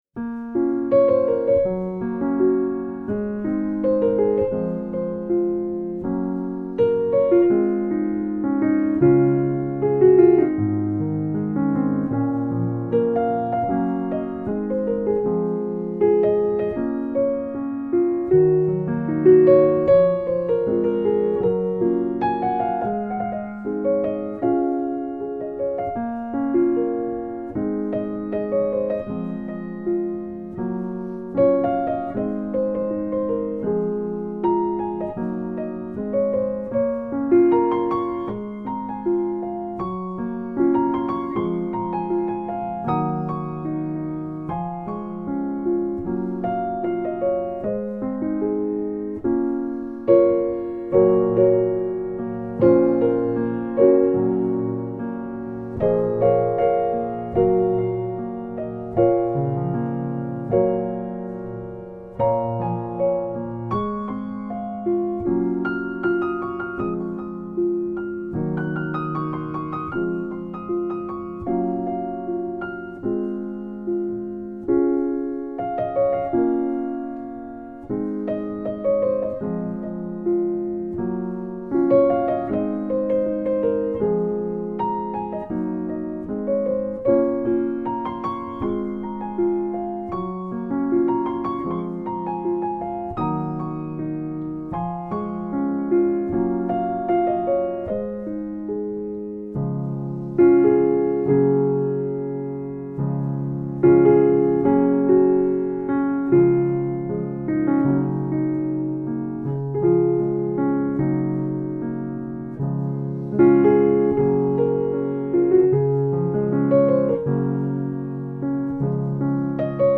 My new solo piano album is now available.